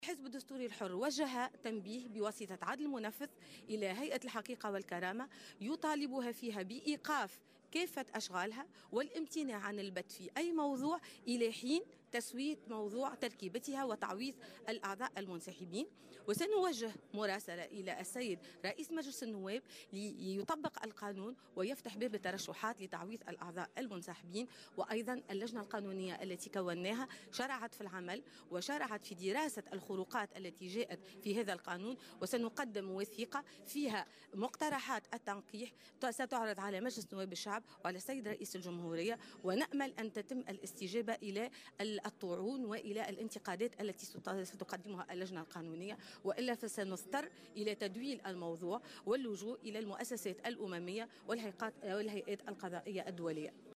وأضافت في تصريح لمراسل "الجوهرة أف أم" أن الحزب سيوجه مراسلة لرئيس مجلس النواب يطالبه فيها بفتح باب الترشحات لتعويض الأعضاء المنسحبين، وفق تعبيرها.